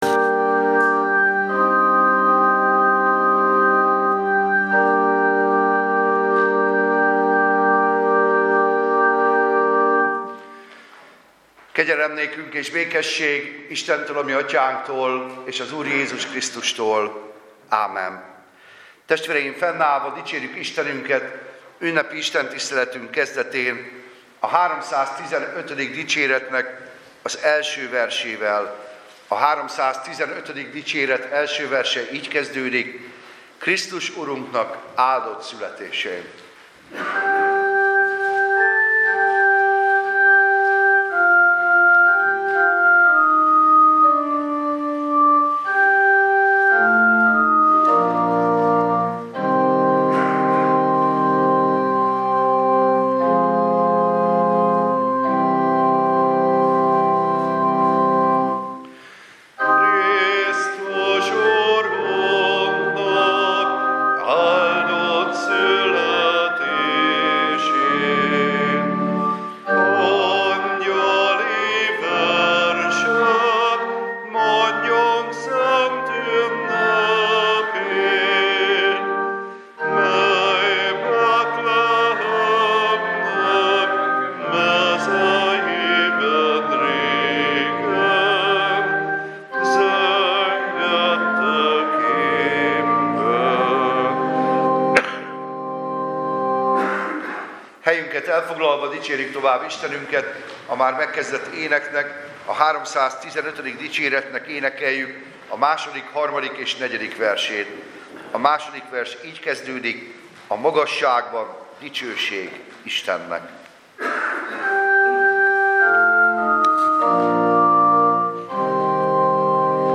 Karácsonyi istentisztelet
Service Type: Igehirdetés